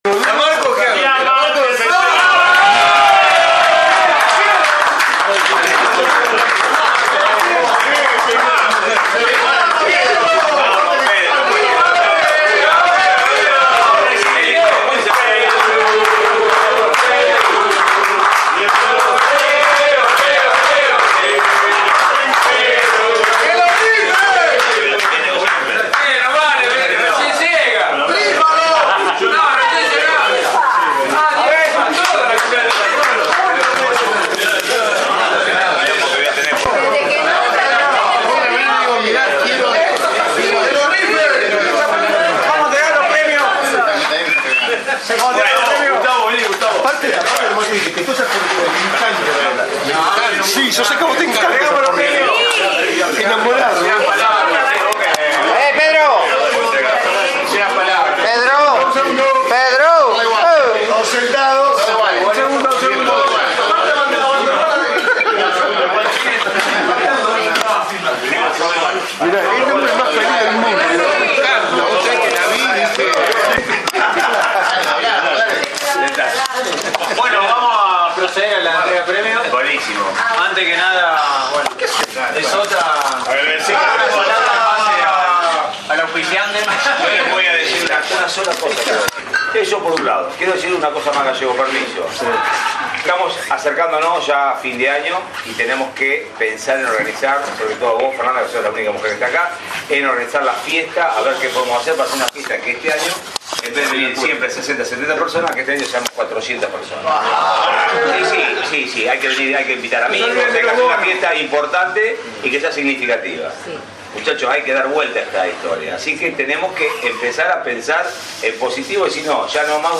Audio: Entrega de premios.